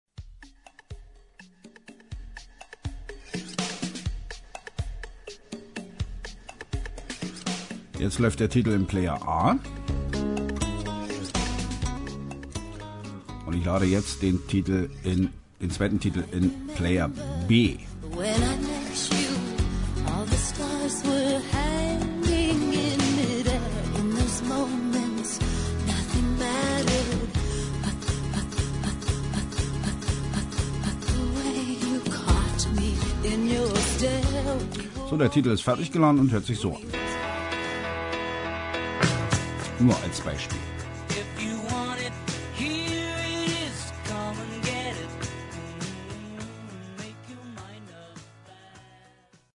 ich hab neuerdings das Problem, wenn im Player A (hab ihn so genannt) ein Titel läuft und lade den nächsten Titel im Player B nach, passiert es, das mAirList kurz einfriert und der grade laufenden Titel stottert bzw. an der gespielten Stelle stehen bleibt und sich solange wiederholt, bis der Titel geladen wurde.